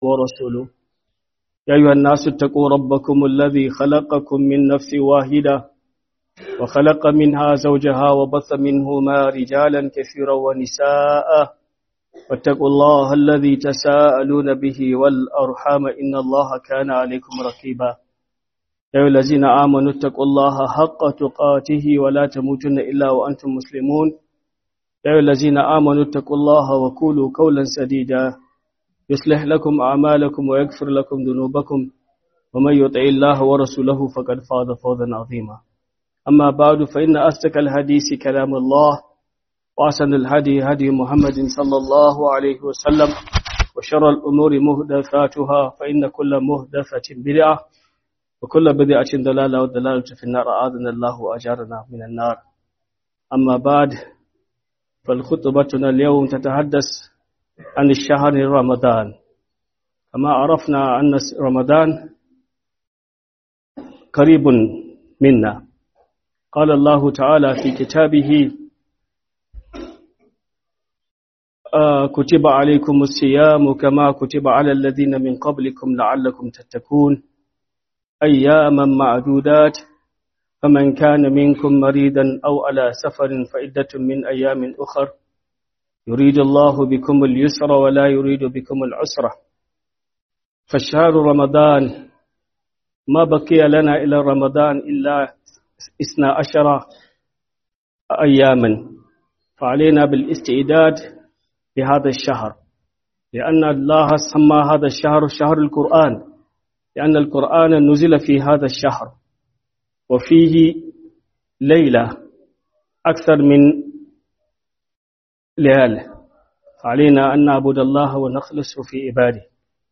Huduba - Maraba da Ramadan